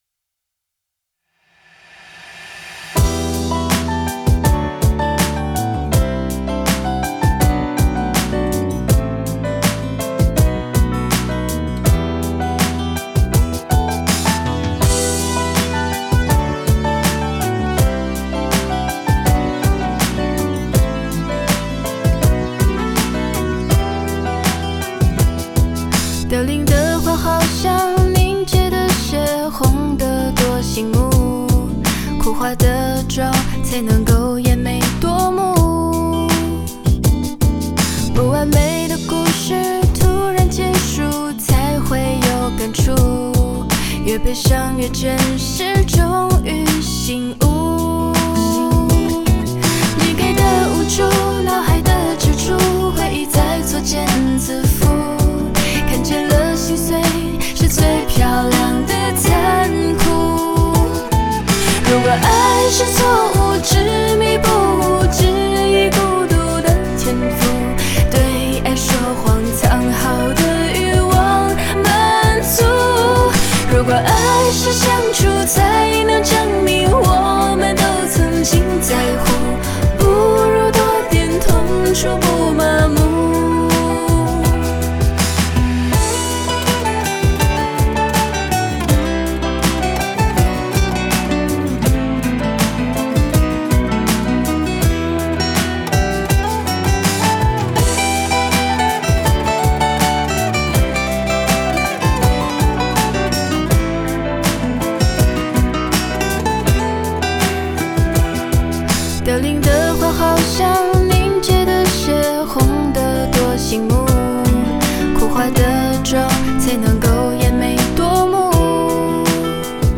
在线试听为压缩音质节选
吉他